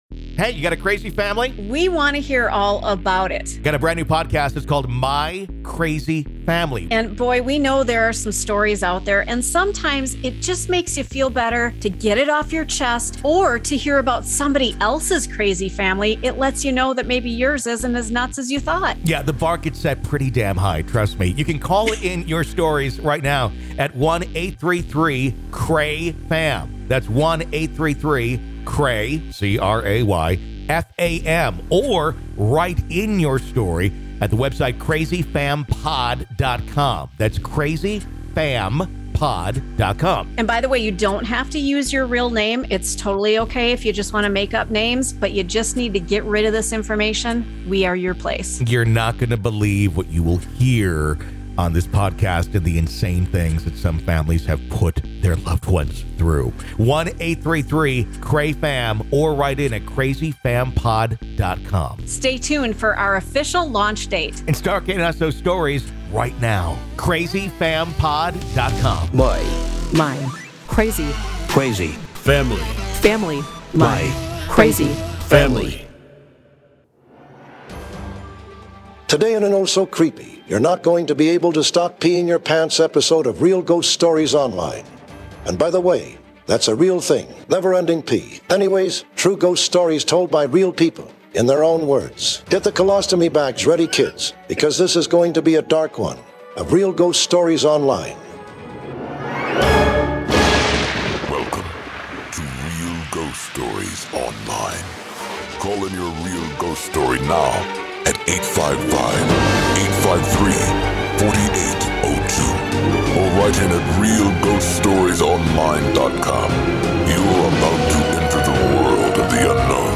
Anyways, True ghost stories told by real people, in their own words.